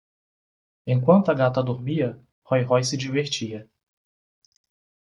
Pronounced as (IPA) /ˈɡa.tɐ/